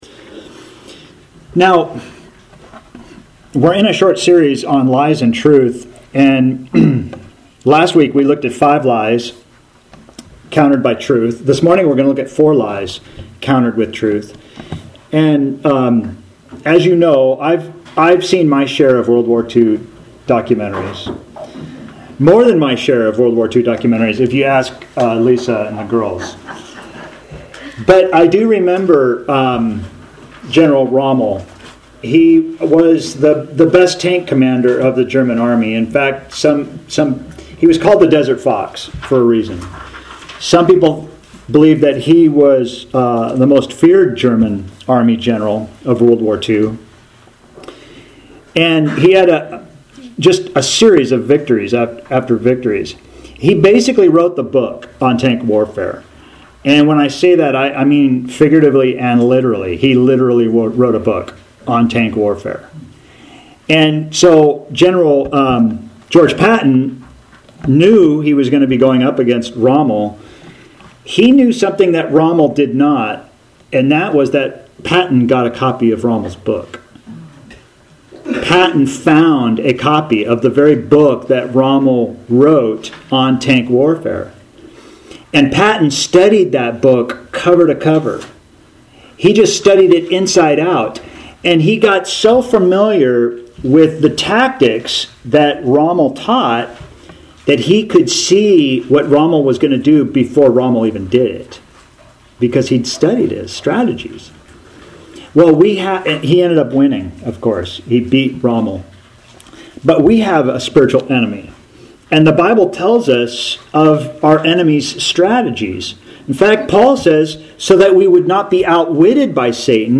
Author jstchurchofchrist Posted on November 8, 2025 Categories Sermons Tags Lies and Truth